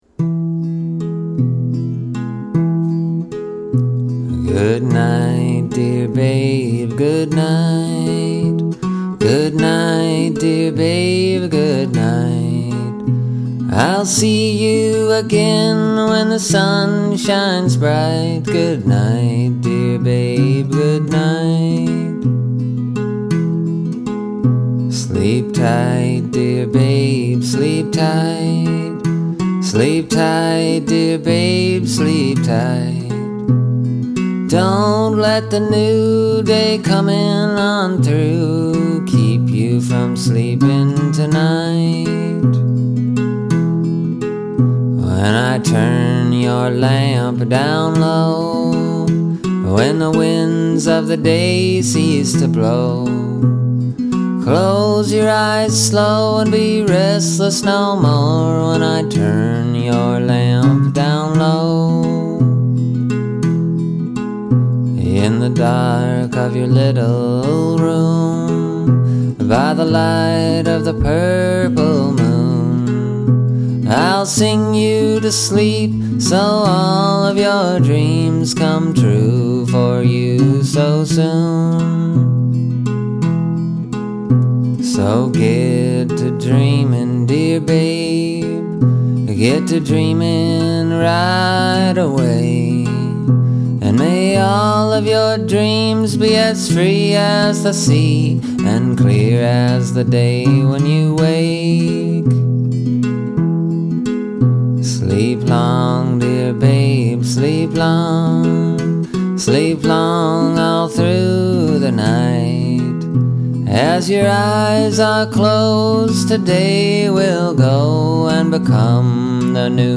It’s a lullaby.